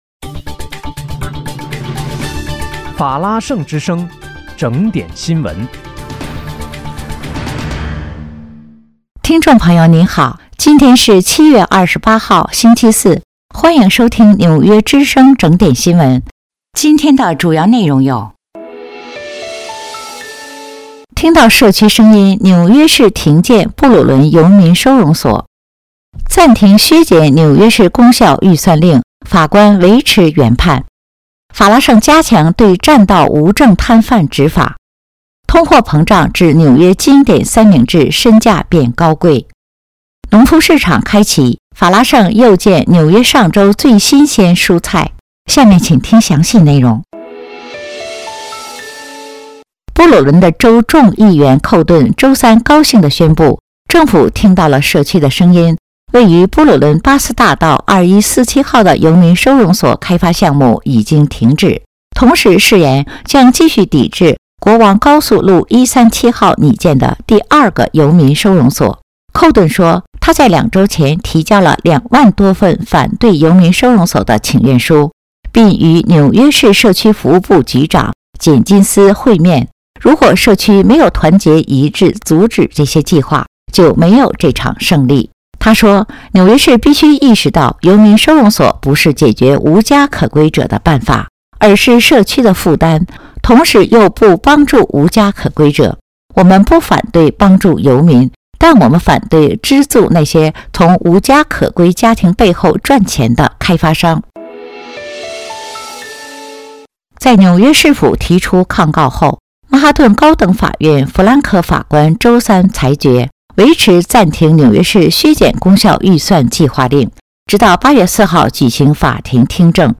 7月28日（星期四）纽约整点新闻